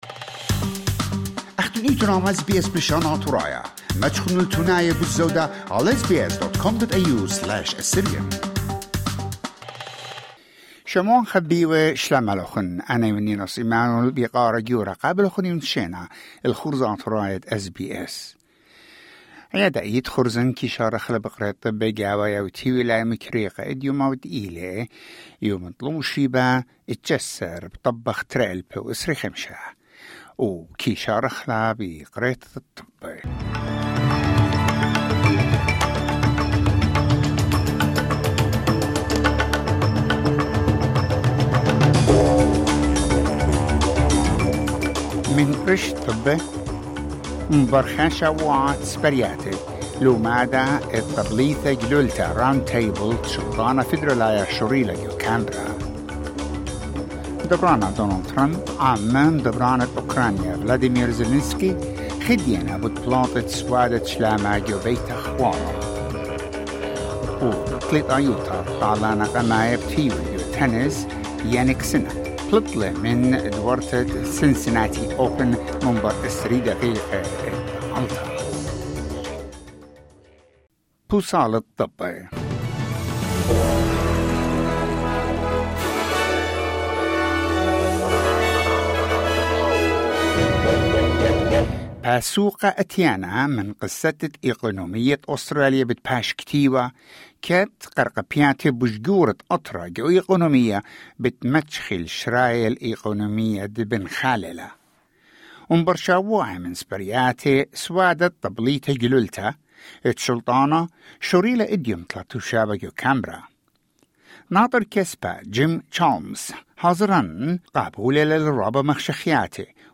News bulletin: 19 August 2025